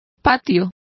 Complete with pronunciation of the translation of courtyard.